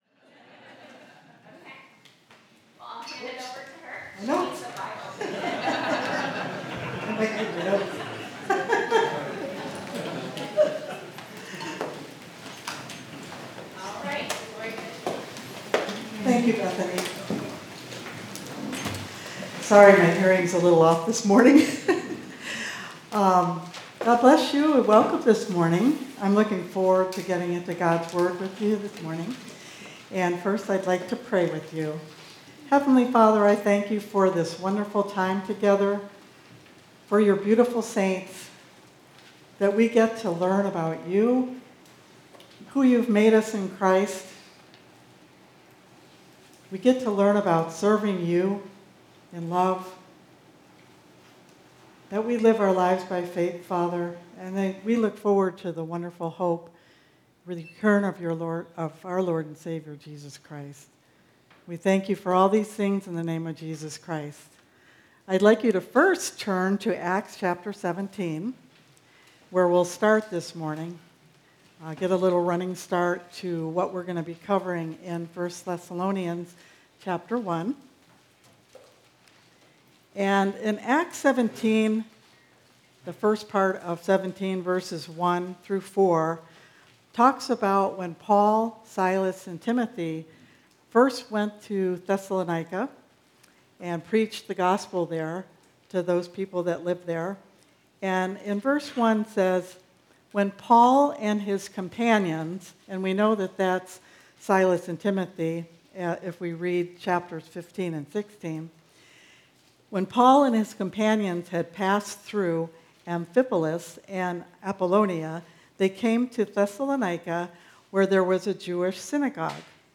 1 Thessalonians 1 Our Daily Hope (Family Camp 2024) – Part 2 July 29, 2024 Part 2 in a verse-by-verse teaching series on 1 and 2 Thessalonians with an emphasis on how our hope helps us to live holy lives until Christ returns.